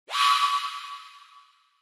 ShiningSfx.mp3